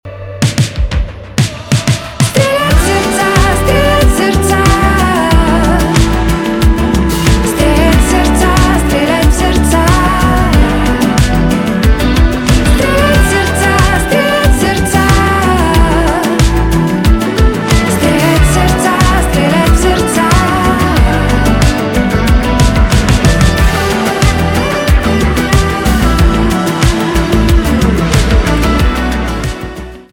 поп
гитара
чувственные